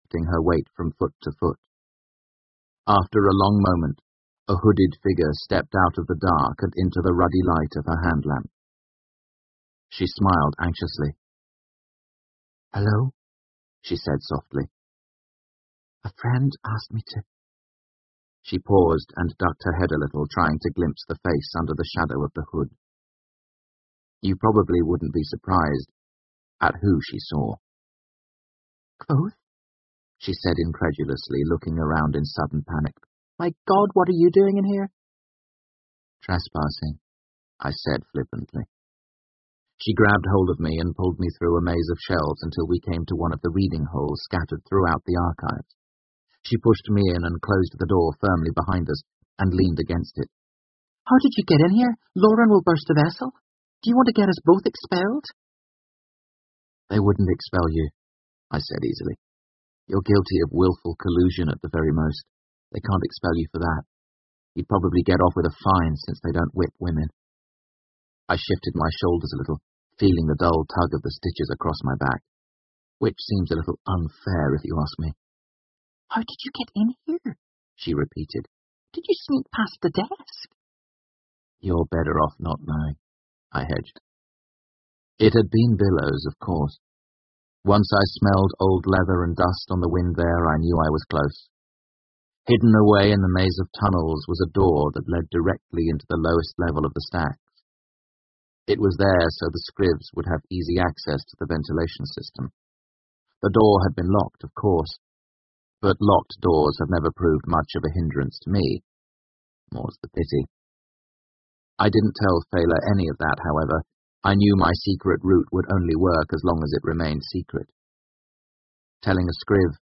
英文广播剧在线听 The Name of the Wind 风之名 182 听力文件下载—在线英语听力室